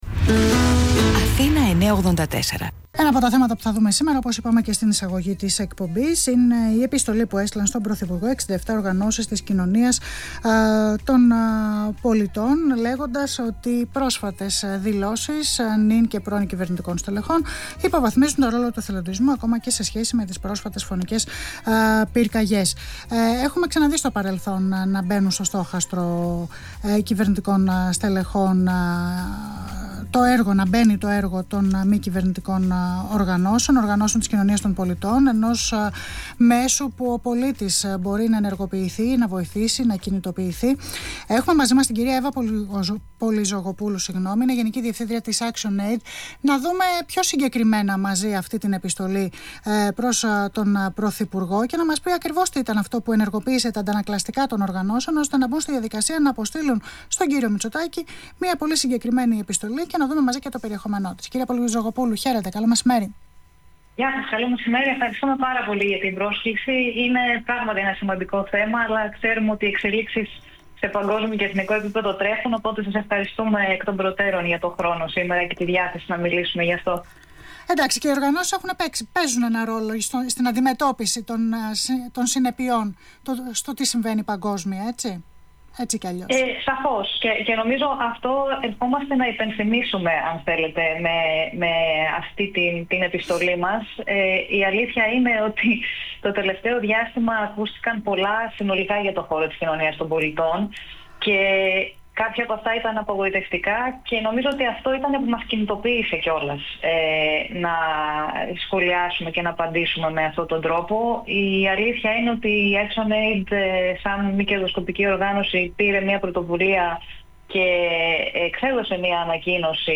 Συνέντευξη στον Αθήνα 9,84: Οι Οργανώσεις της Κοινωνίας των Πολιτών είναι σύμμαχος της πολιτείας στην κοινωνική συνοχή
Συνέντευξη